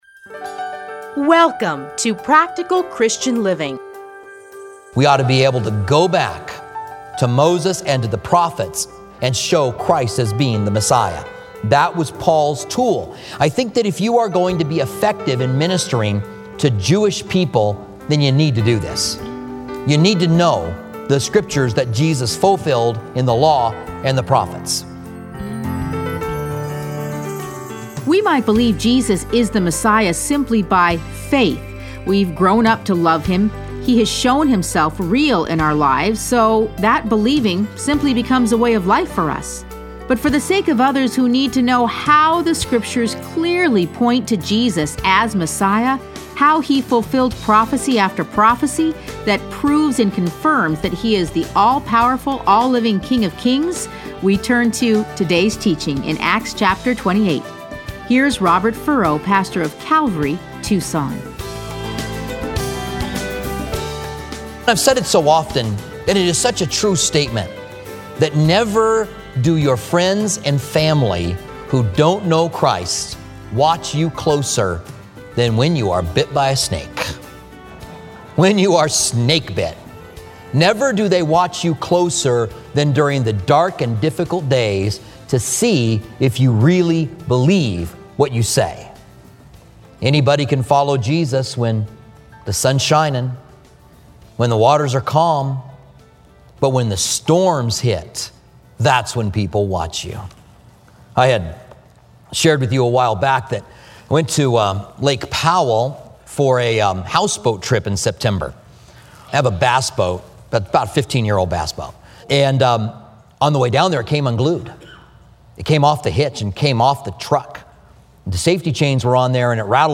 Listen to a teaching from Acts 28.